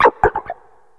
Cri de Nigirigon dans sa forme Raide dans Pokémon HOME.
Cri_0978_Raide_HOME.ogg